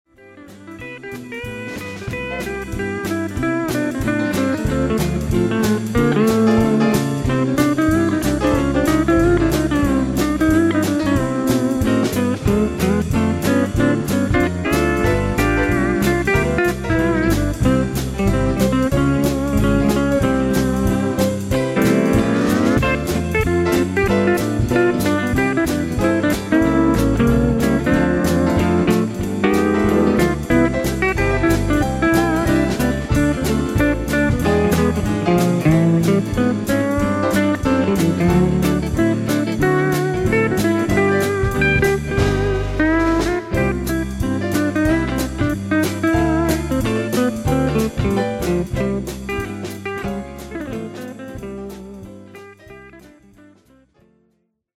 guitar
keyboards
violin and mandolin